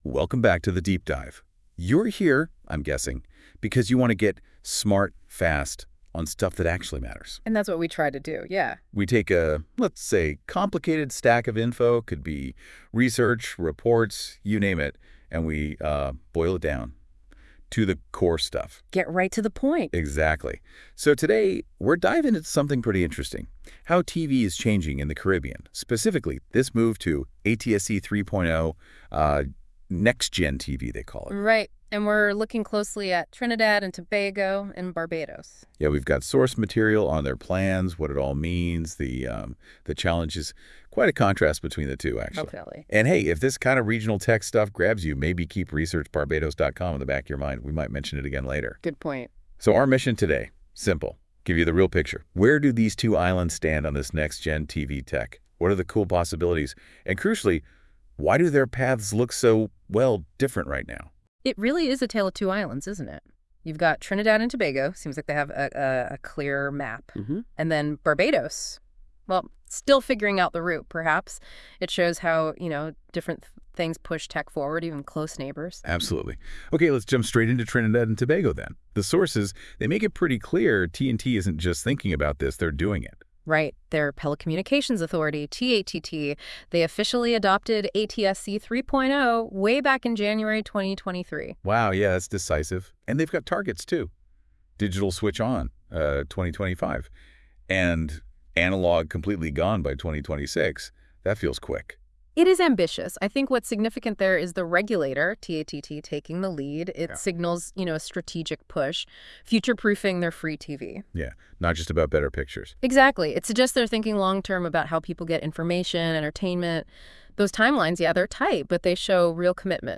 Expert discussion on ATSC 3.0 implementation challenges and opportunities